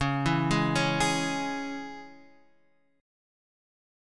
C#m chord